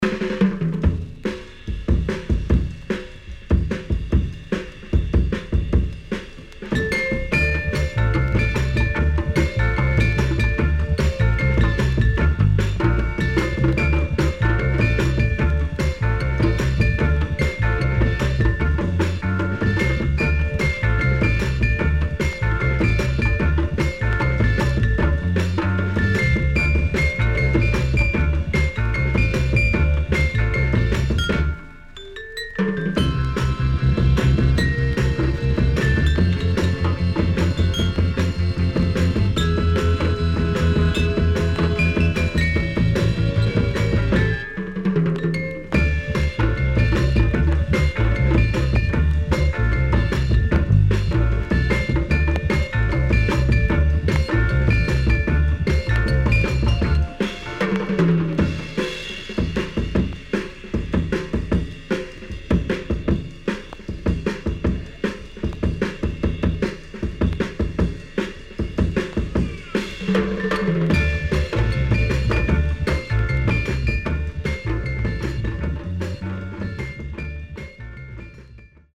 SIDE A:プレス起因により少しチリノイズ、プチノイズ入ります。